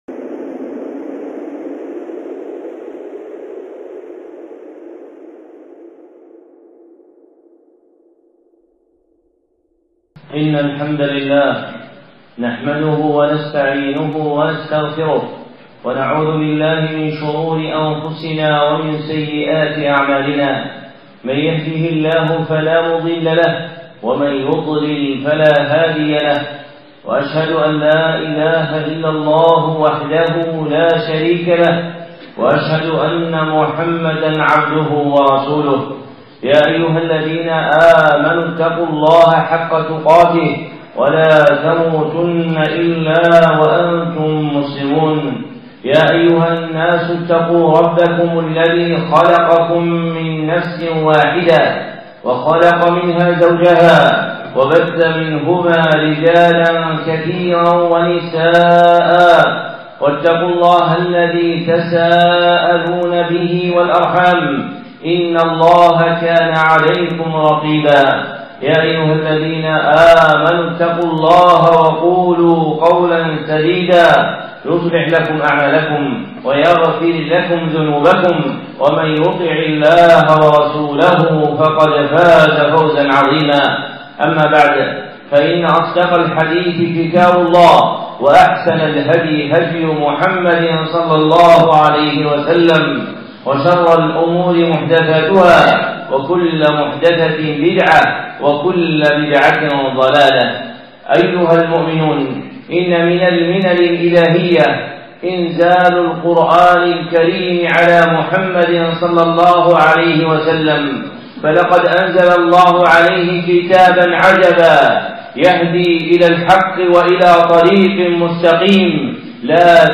خطبة (فضل سورة الإخلاص